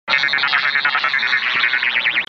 دانلود آهنگ رادیو 21 از افکت صوتی اشیاء
دانلود صدای رادیو 21 از ساعد نیوز با لینک مستقیم و کیفیت بالا
جلوه های صوتی